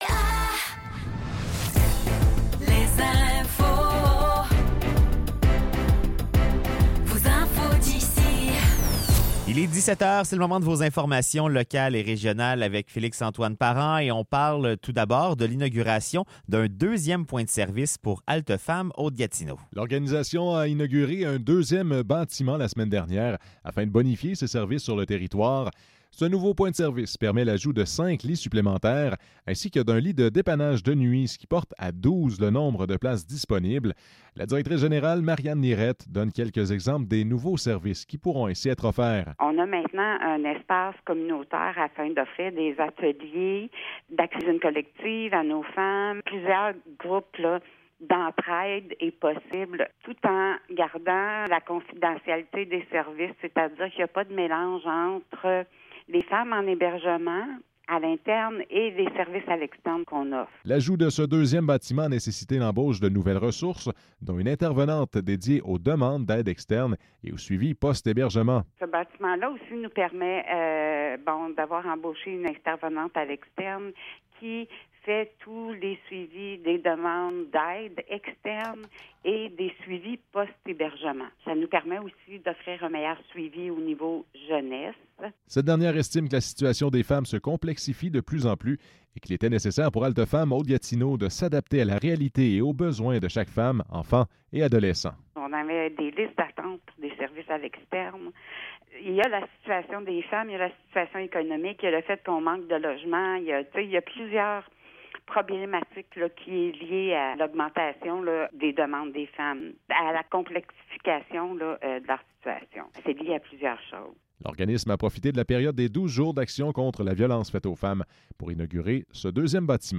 Nouvelles locales - 2 décembre 2024 - 17 h